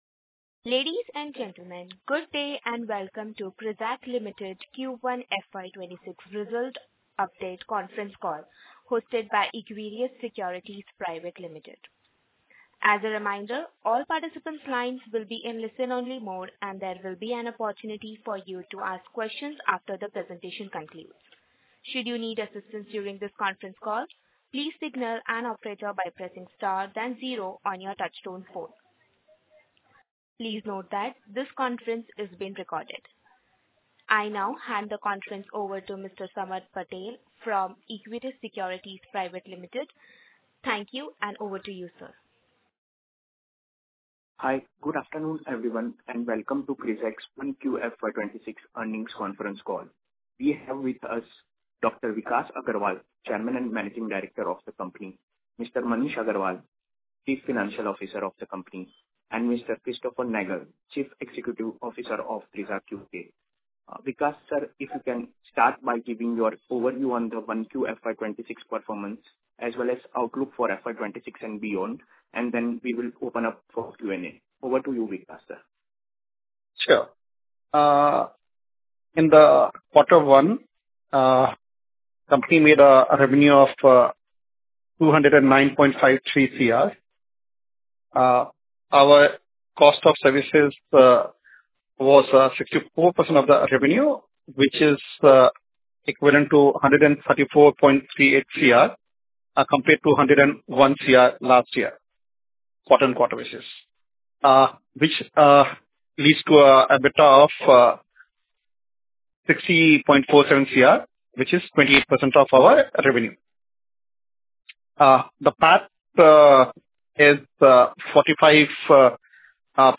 Concalls
audio-recording-of-investor-call.mp3